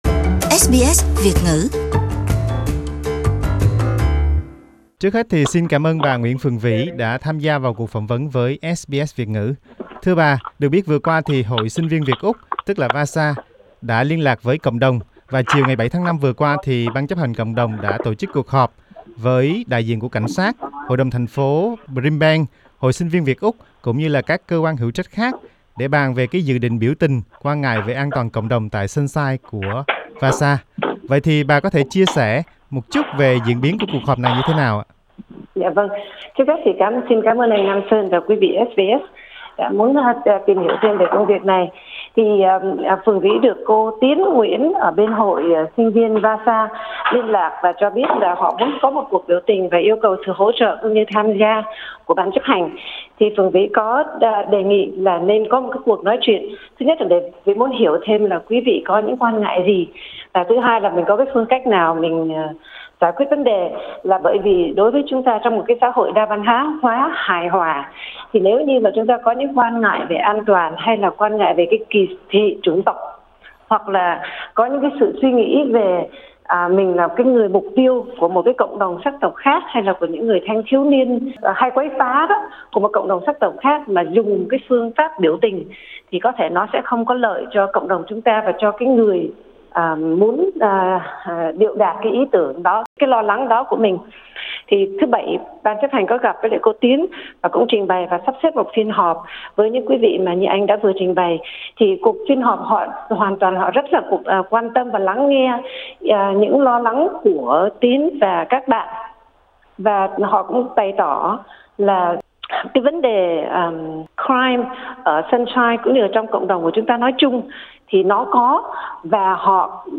Xin bấm vào Audio ở trên để nghe trọn cuộc phỏng vấn